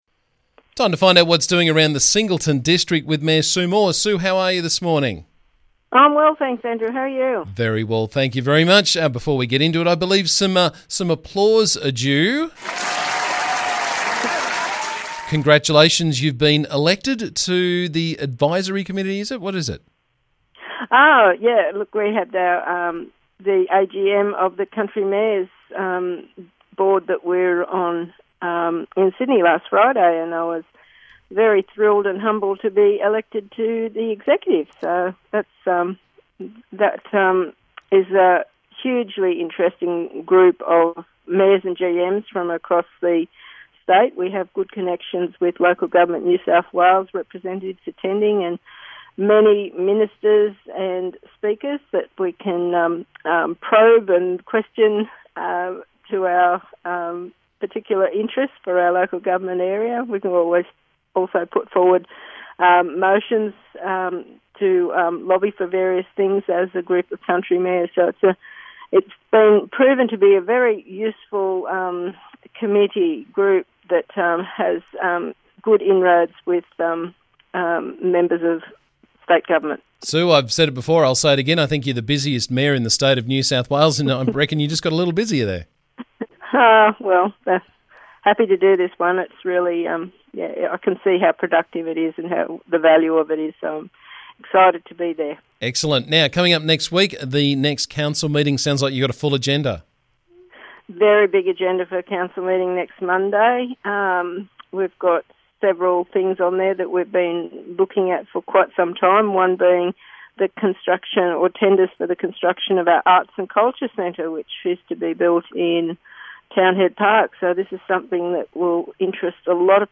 Every couple of weeks we catch up with Singleton Council Mayor Sue Moore to find out what's happening around the district.